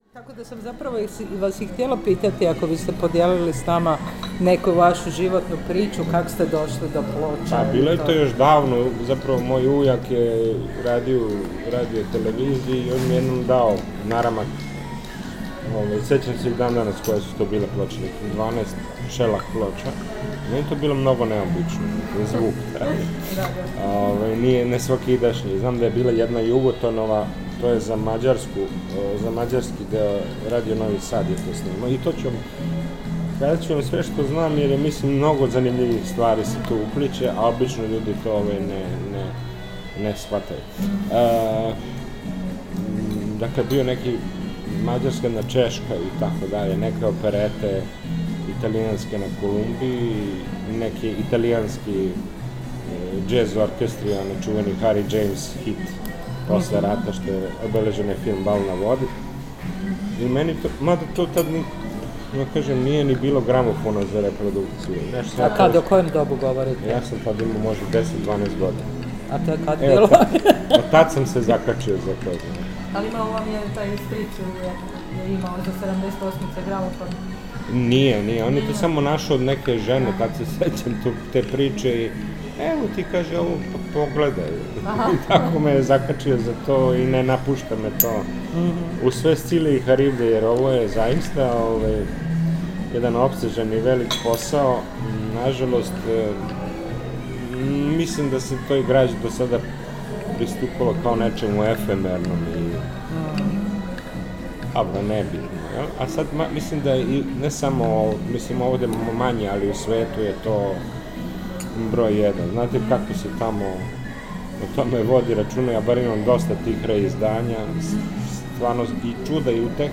donosimo isječak iz dijela razgovora o počecima njegova zanimanja za gramofonske ploče, važnosti očuvanja zvučne baštine i o problemima restauracije zvuka, digitalno urezanog dana 16. srpnja 2021. oko 19 sati u kavani hotela Majestic u Beogradu.